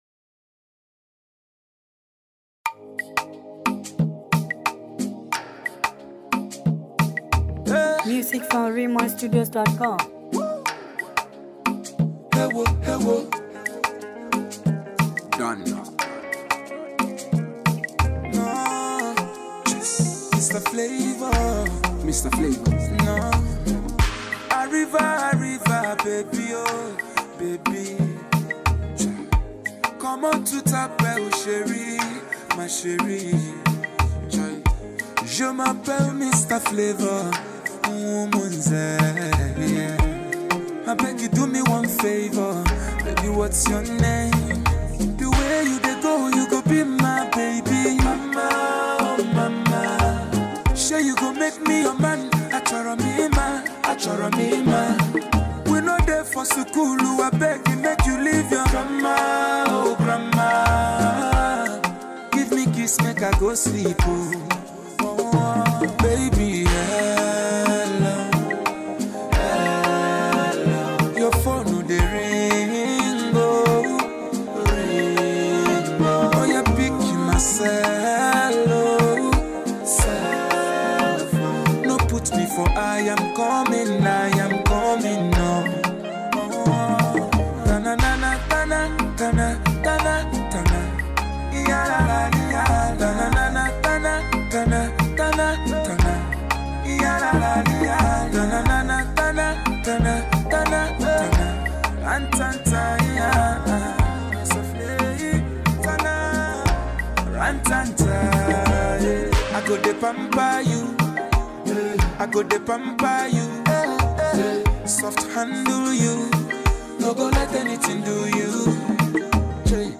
Nigerian highlife king
melodious single
lovely-dovey tune